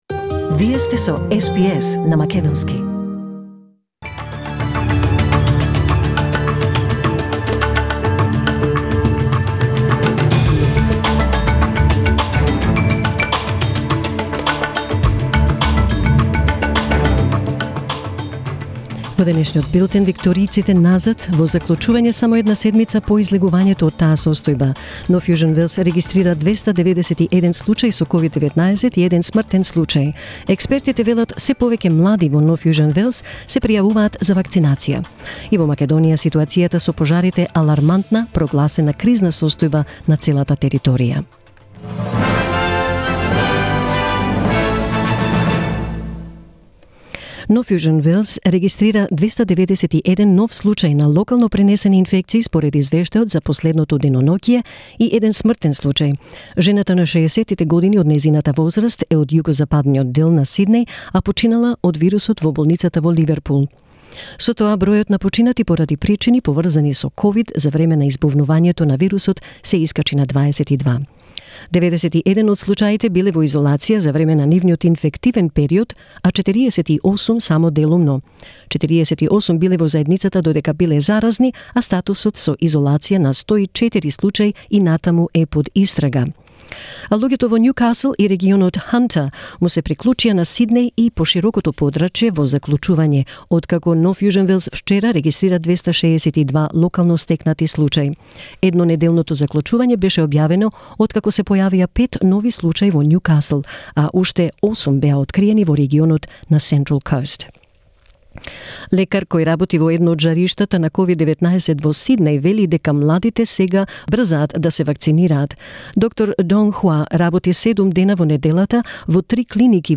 SBS News in Macedonian 6 August 2021